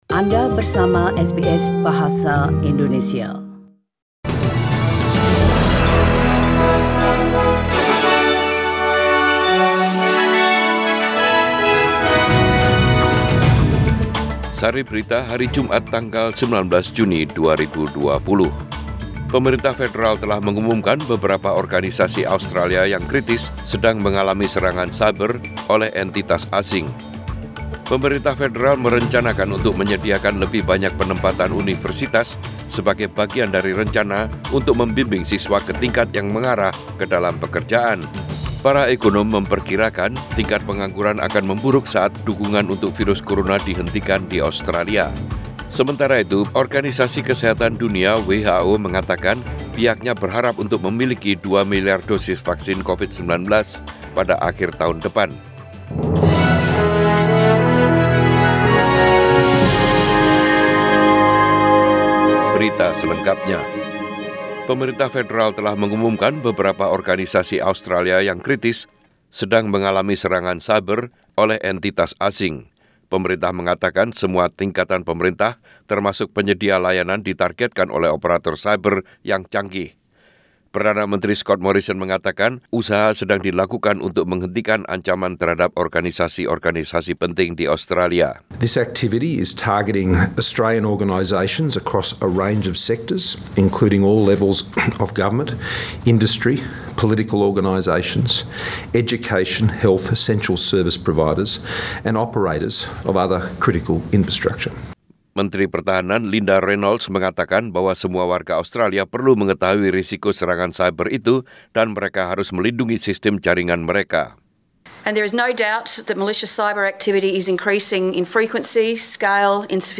SBS Radio News in Bahasa Indonesia - 19 June 2020